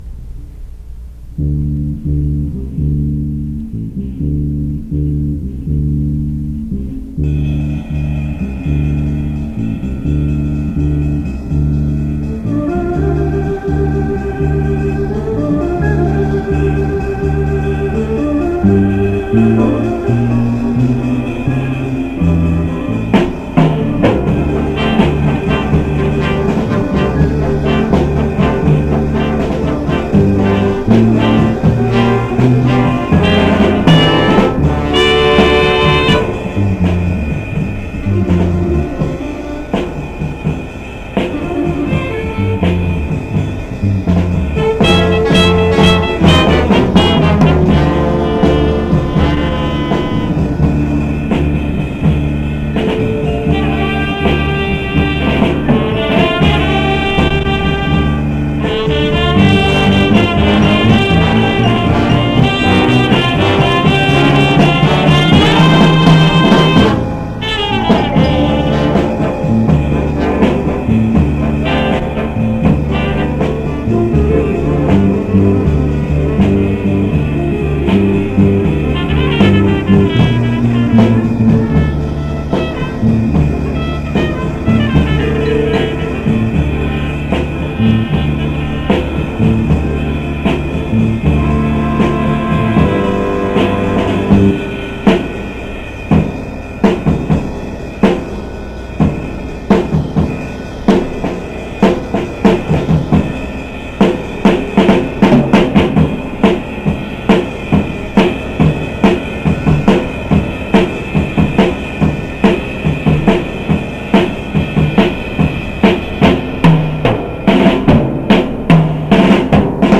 washington high school: 1969 summer jazz band